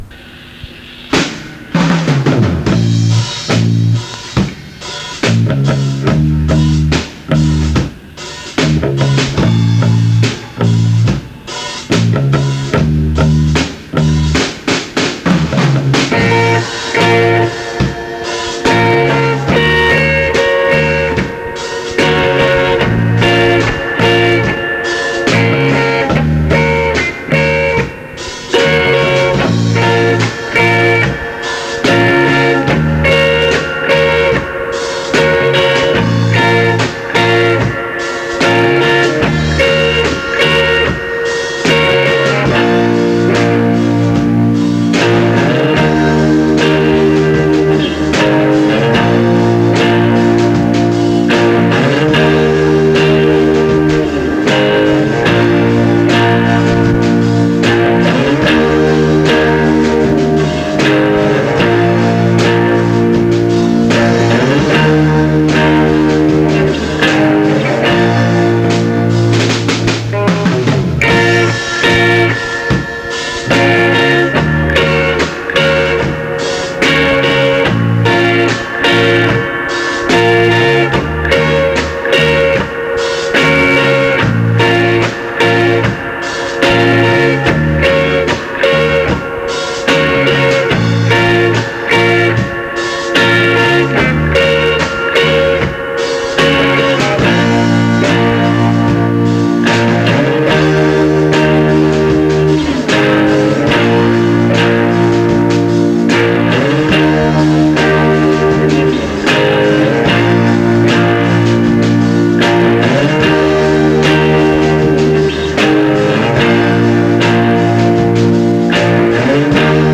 basse
batterie
guitare
chant
guitare rythmique
Reggae
reggae.mp3